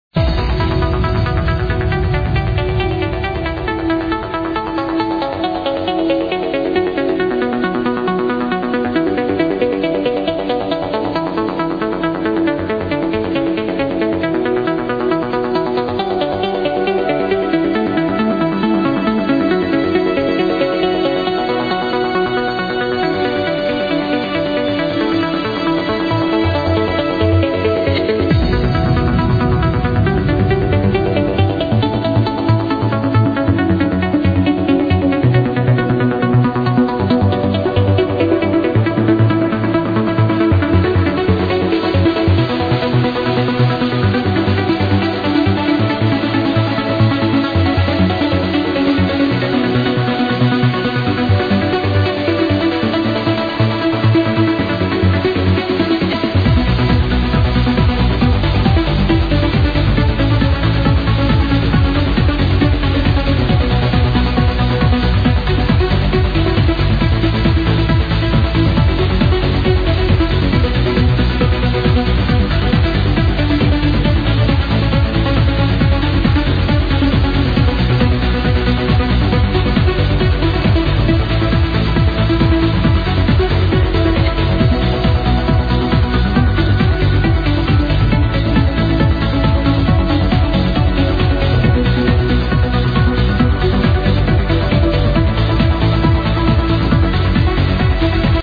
Exclamation ID =-> Beautiful Trance Track
IMO, an amazingly beautiful track, needs ID badly!